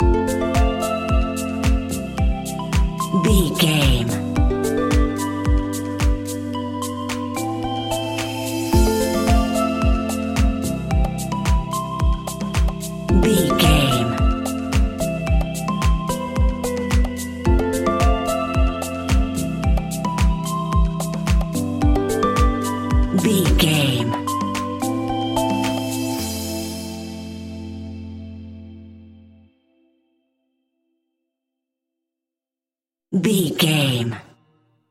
Aeolian/Minor
groovy
peaceful
meditative
smooth
drum machine
synthesiser
house
electro house
funky house
instrumentals
synth leads
synth bass